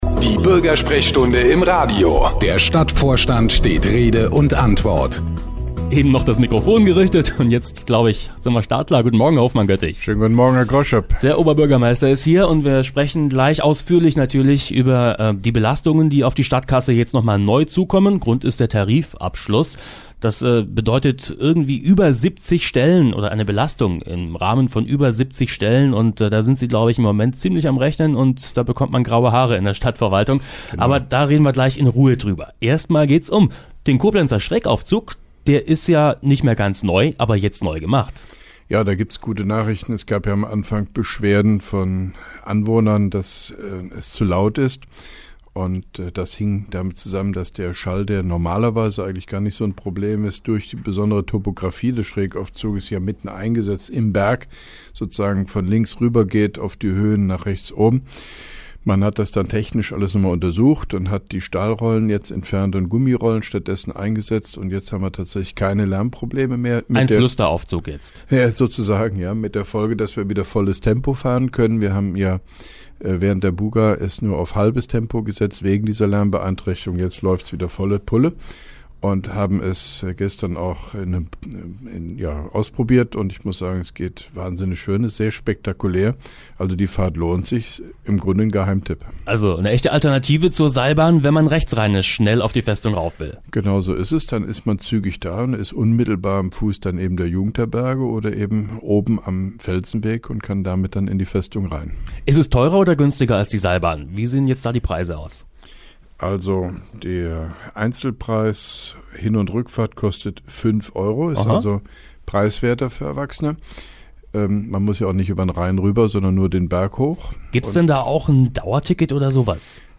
(1) Koblenzer Radio-Bürgersprechstunde mit OB Hofmann-Göttig 03.04.2012